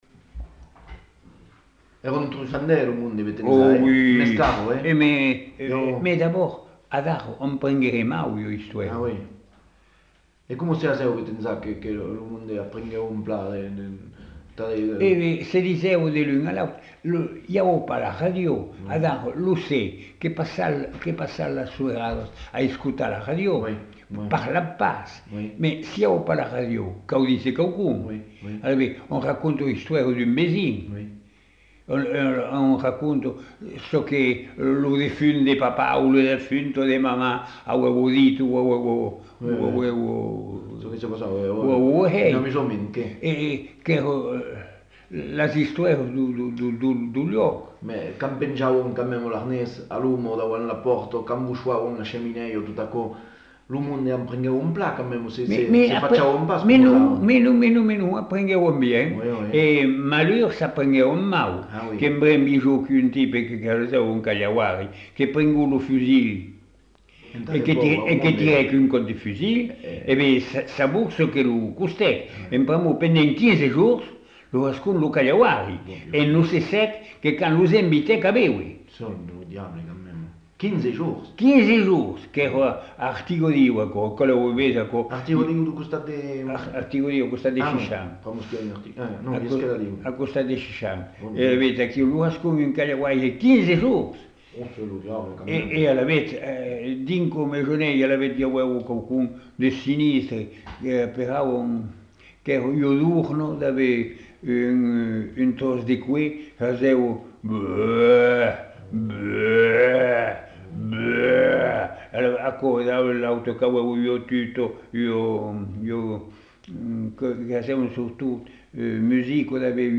Lieu : Masseube
Genre : témoignage thématique